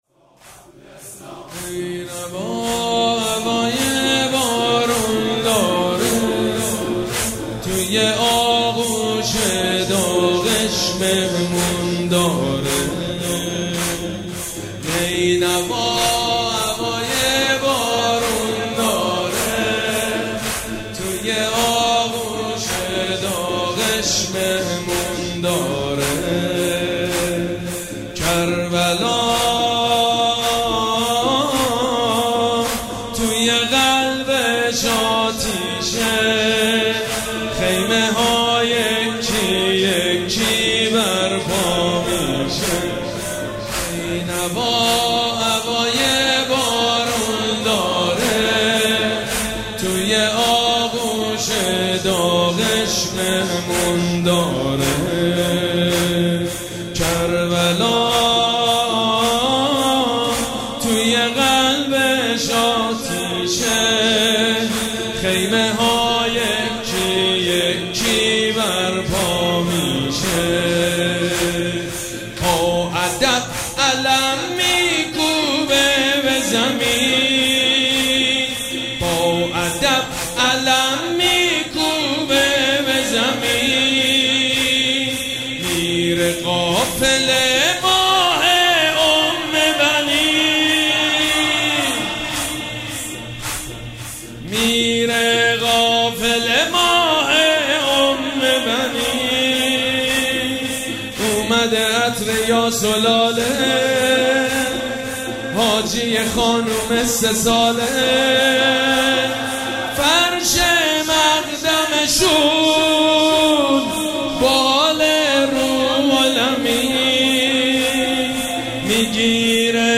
مراسم عزاداری شب دوم محرم الحرام ۱۴۴۷
حاج سید مجید بنی فاطمه